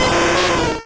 Cri d'Arcanin dans Pokémon Rouge et Bleu.